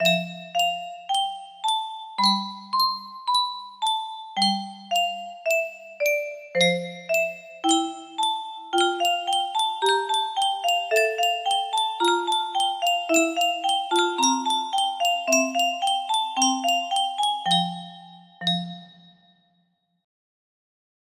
10512 music box melody
Grand Illusions 30 (F scale)